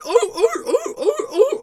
seal_walrus_2_bark_02.wav